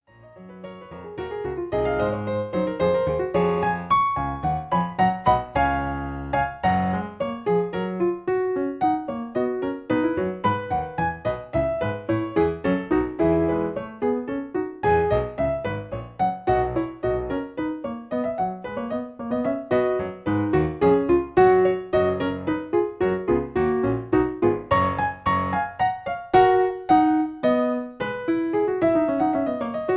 In B. Piano Accompaniments